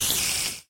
mob / spider / say1.ogg